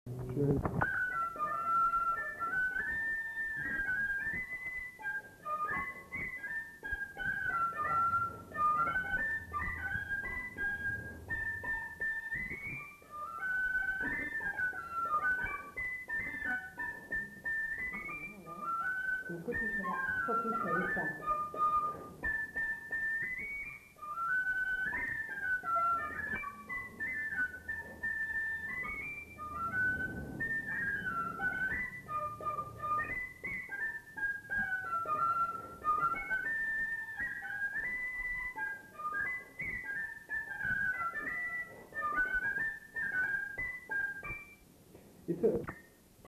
Aire culturelle : Bazadais
Lieu : Bazas
Genre : morceau instrumental
Instrument de musique : fifre
Danse : scottish